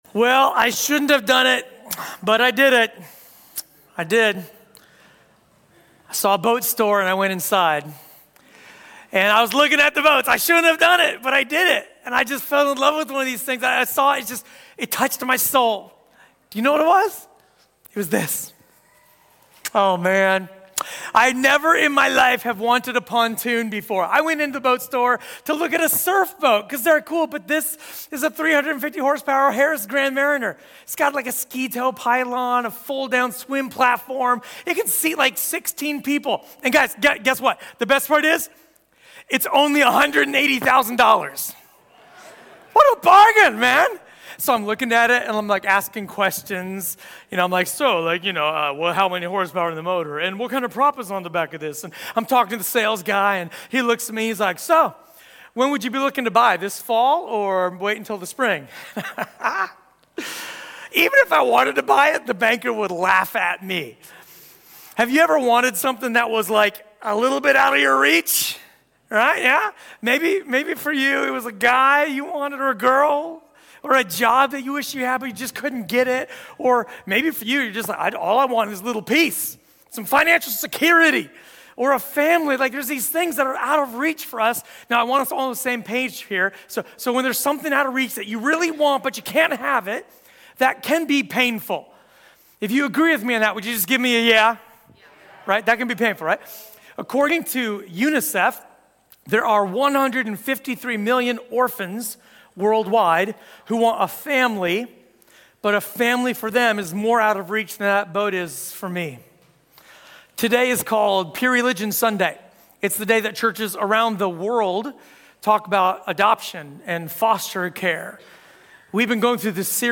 A sermon from the series "Revolution Sermon."